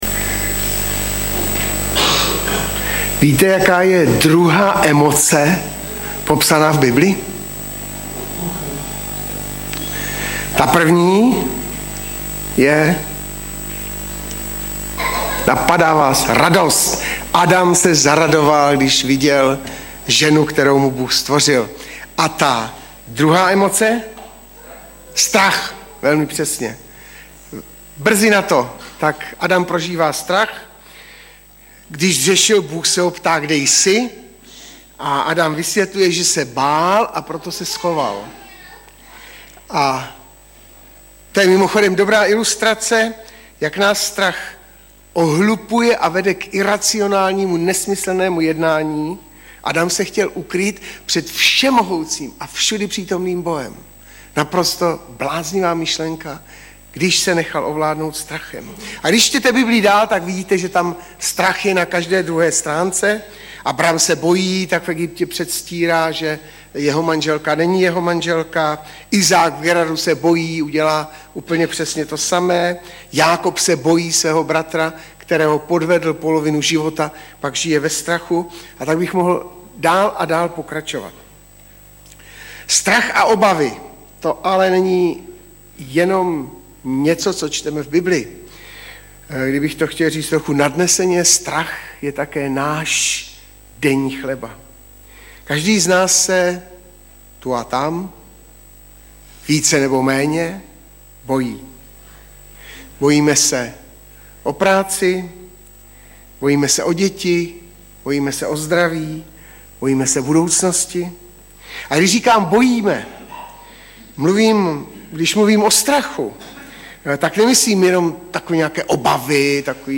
Webové stránky Sboru Bratrské jednoty v Litoměřicích.
Audiozáznam kázání si můžete také uložit do PC na tomto odkazu.